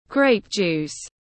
Nước nho ép tiếng anh gọi là grape juice, phiên âm tiếng anh đọc là /ɡreɪp ˌdʒuːs/